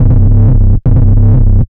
Index of /90_sSampleCDs/Club_Techno/Bass Loops
BASS_140_1-C#.wav